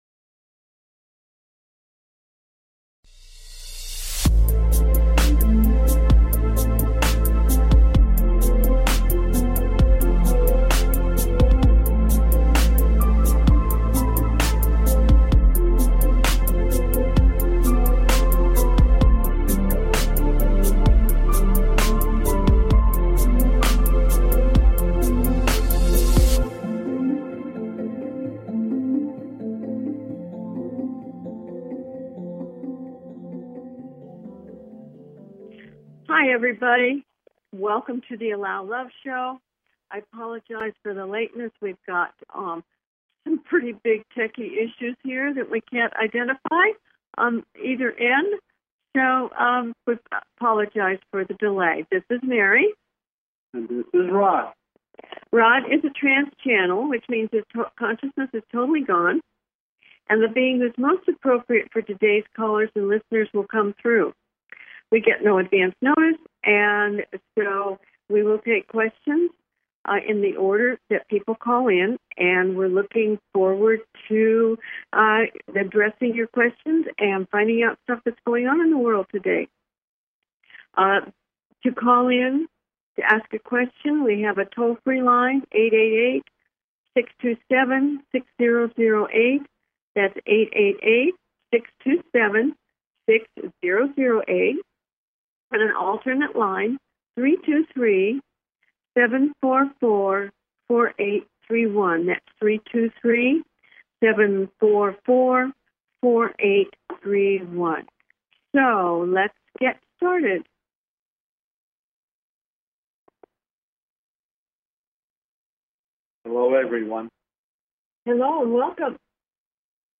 Talk Show
Their purpose is to provide answers to callers’ questions and to facilitate advice as callers request.